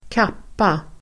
Uttal: [²k'ap:a]